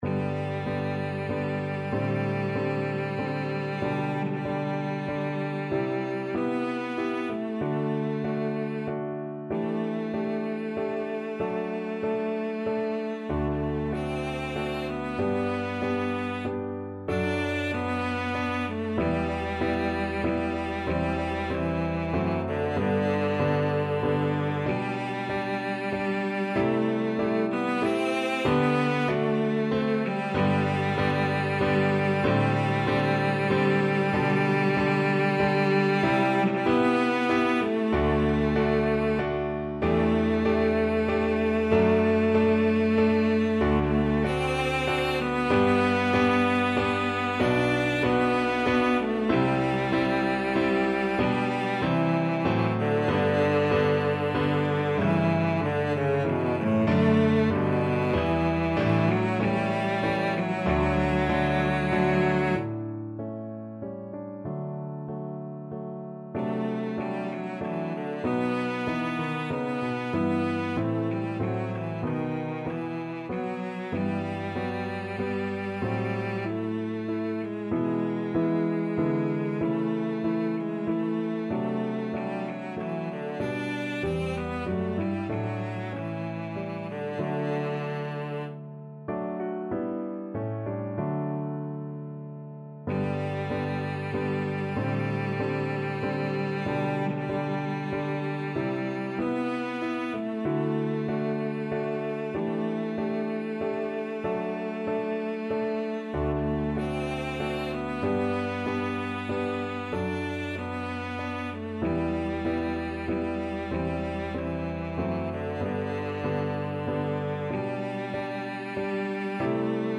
Classical Handel, George Frideric Thanks Be to Thee (Dank sei dir Herr) Cello version
Cello
3/4 (View more 3/4 Music)
G major (Sounding Pitch) (View more G major Music for Cello )
=95 Andante
Classical (View more Classical Cello Music)